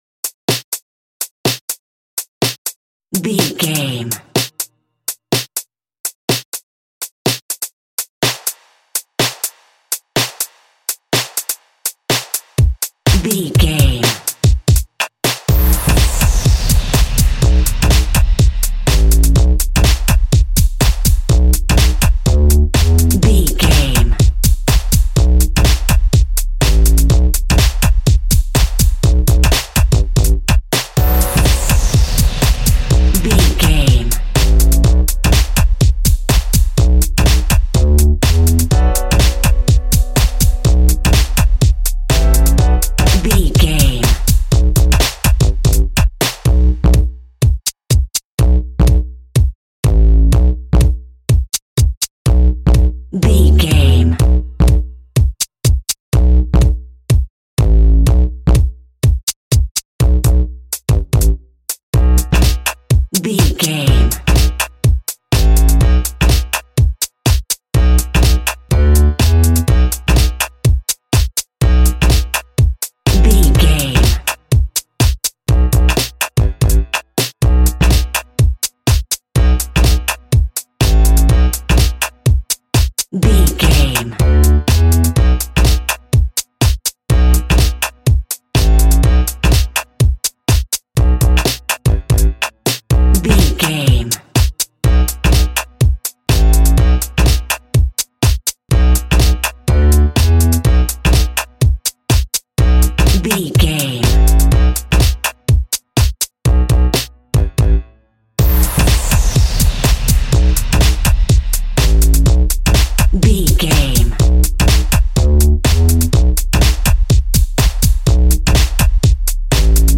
Aeolian/Minor
groovy
uplifting
driving
energetic
bass guitar
synthesiser
electric guitar
drums
piano
nu disco
upbeat
instrumentals